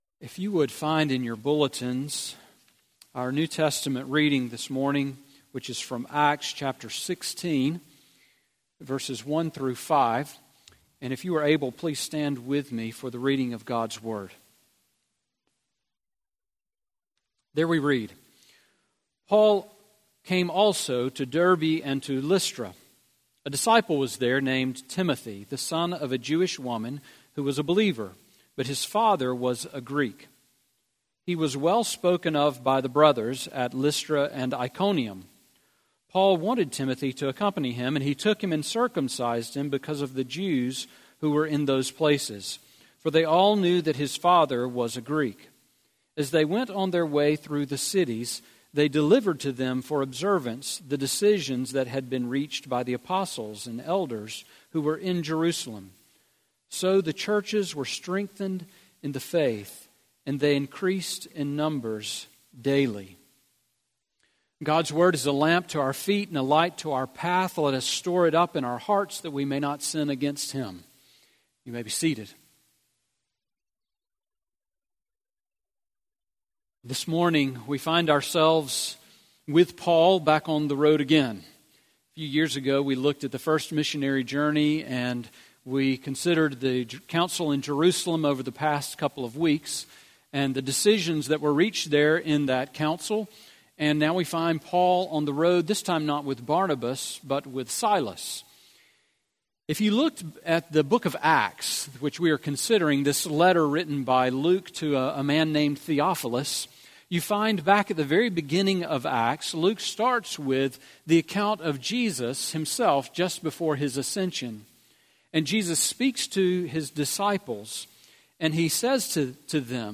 Sermon on Acts 16:1-5 from April 22